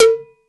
KW Snr T.wav